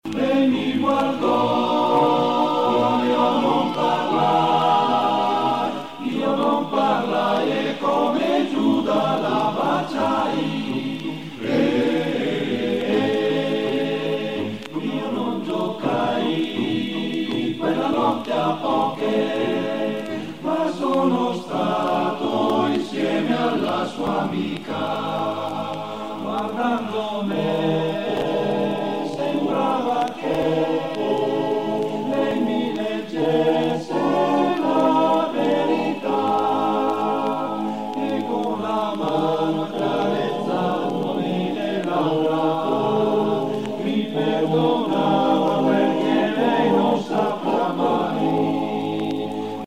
te las quería enseñar… las canciones alpinas, como ésta…oís?
CoroAlpinoMilanese.mp3